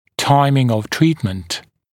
[‘taɪmɪŋ əv ‘triːtmənt][‘таймин ов ‘три:тмэнт]выбор времени для лечения